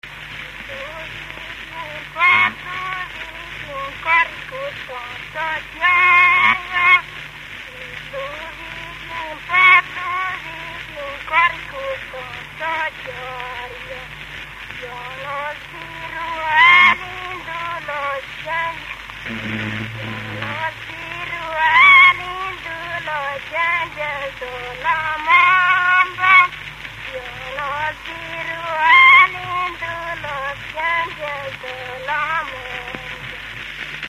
Moldva és Bukovina - Moldva - Bogdánfalva
ének
Stílus: 7. Régies kisambitusú dallamok
Szótagszám: 8.7.8.7
Kadencia: (5) 1